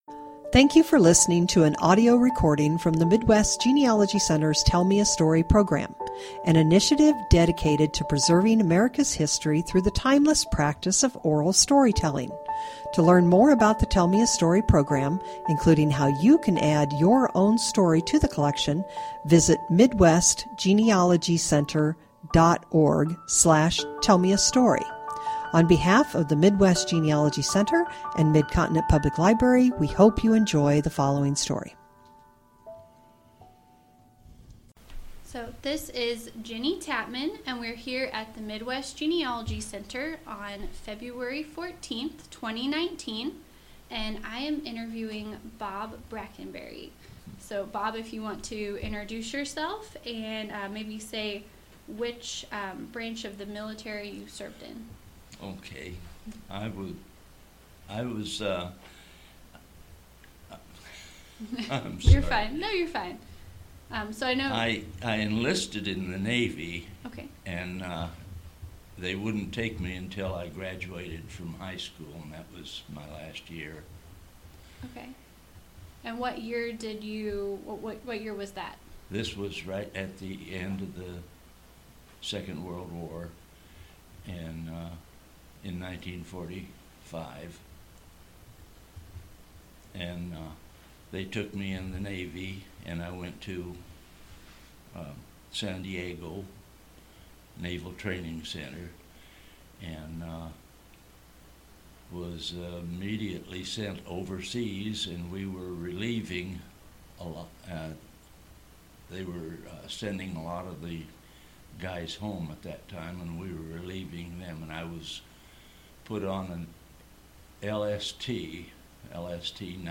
Oral History Veterans Interview